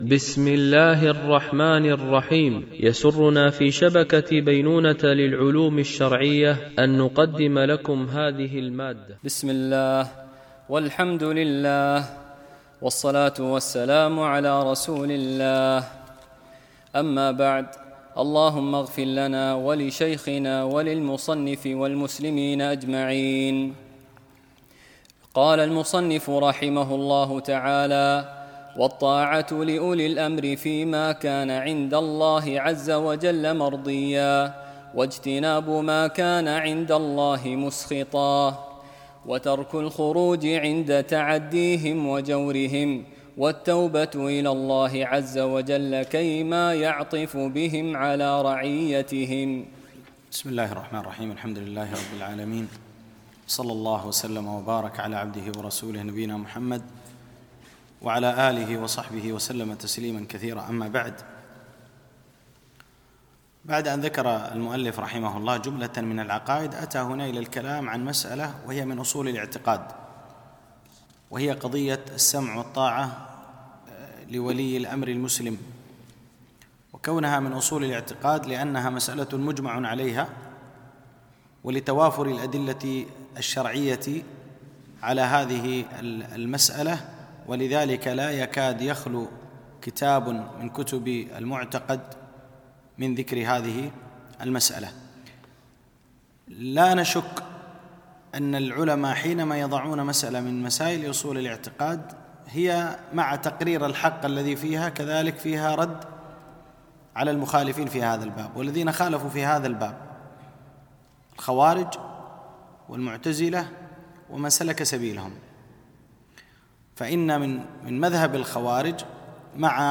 MP3 Mono 44kHz 96Kbps (VBR)